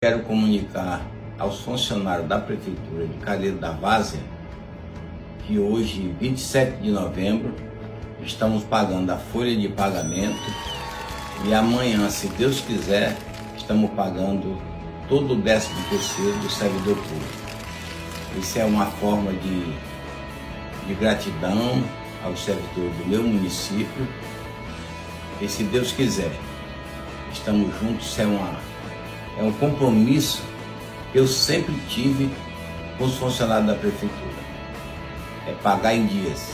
Ouça trecho da mensagem do Prefeito do Careiro da Várzear, Pedro Guedes: